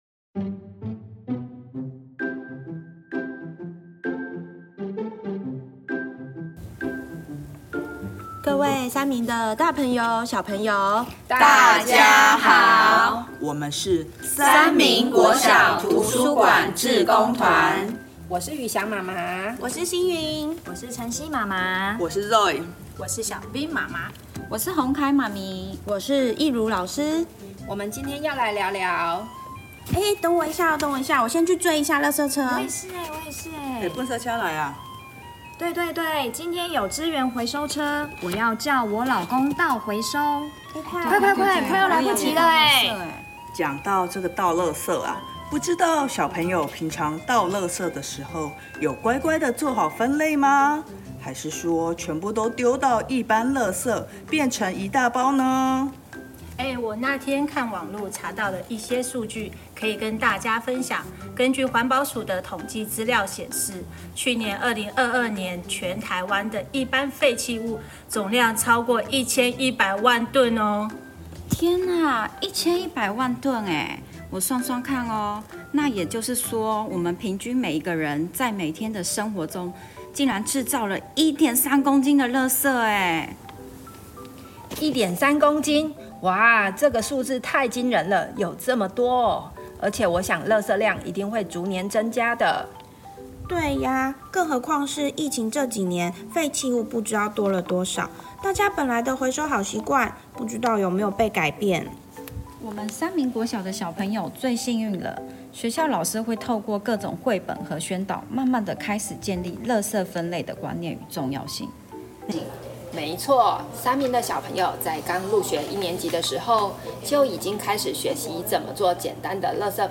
【悅讀閱愛讀】圖書館SDGs主題-環保廣播劇「什麼垃圾不能回收？」邀請親師生一起來聆聽!
圖書館志工夥伴們錄製一齣貼近新竹在地生活的環保廣播劇-「什麼垃圾不能回收？」 貼近真實生活情境的廣播劇，邀請親師生一起來聆聽~成為改變世界的行動者!